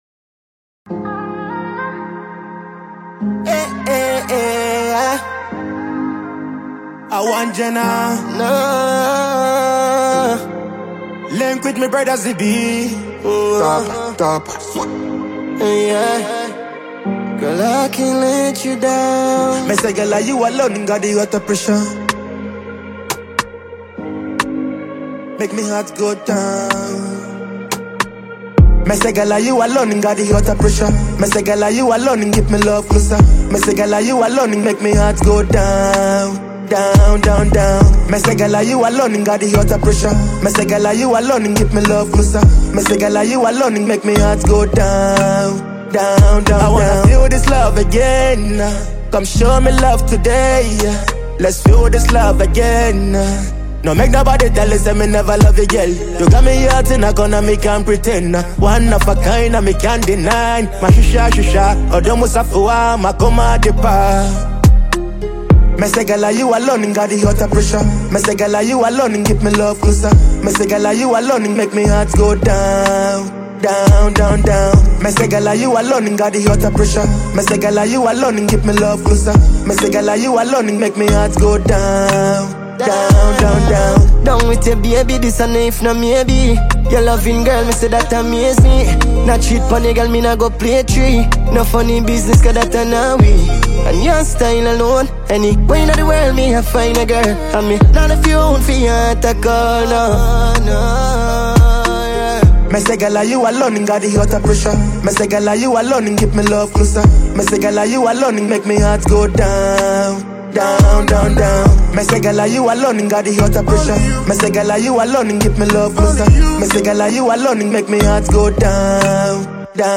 Afrobeat
an Afrobeat-leaning song with a high-energy, rhythmic feel